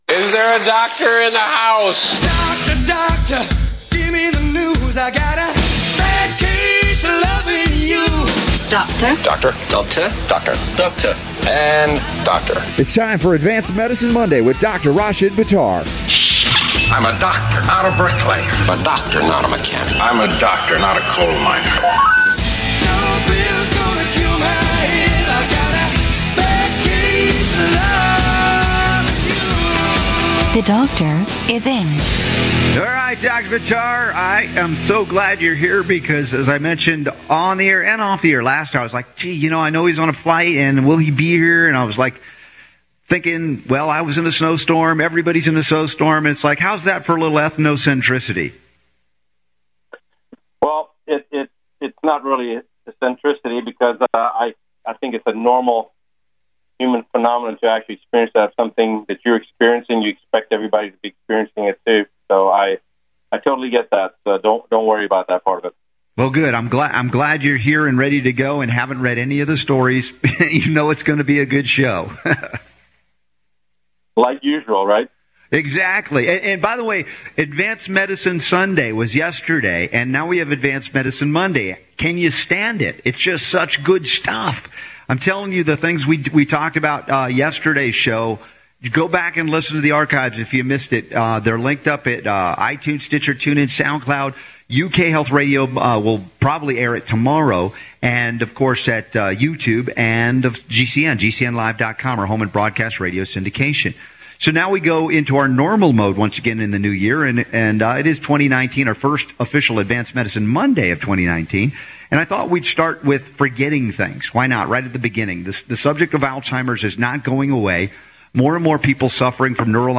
Advanced Medicine Radio Show | 1-7-2019 Get ready to learn things not traditionally taught to medical doctors!